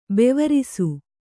♪ bevarisu